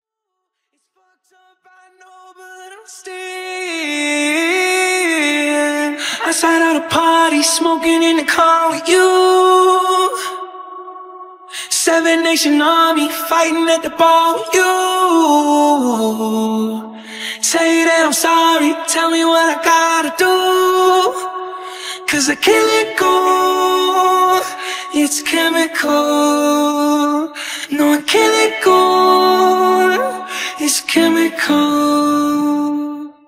DEMO UPDATE :